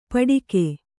♪ paḍike